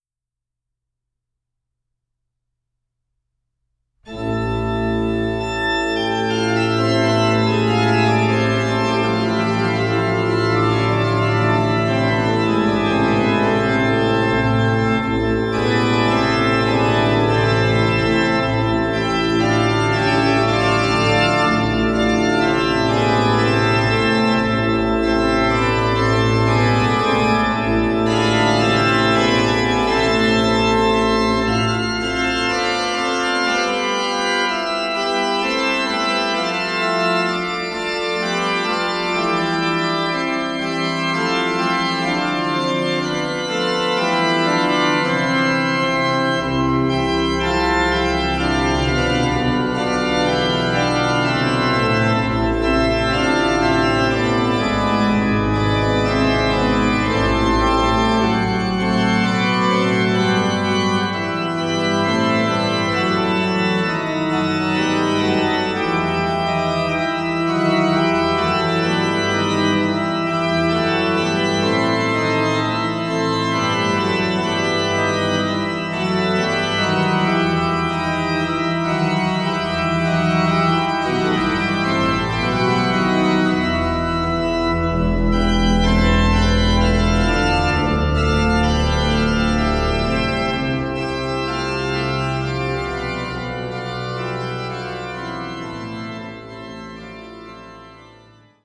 concerti transcrits pour l’orgue